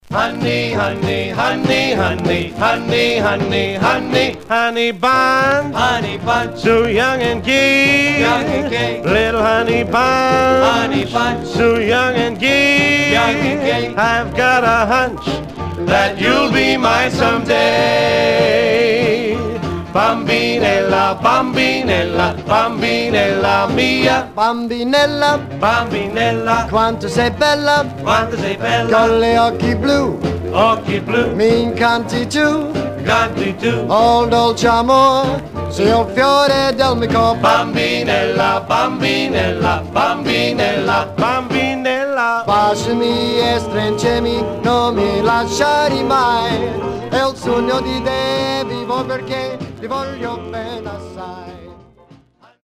Stereo/mono Mono
White Group Pop Jiver